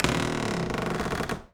door_A_creak_05.wav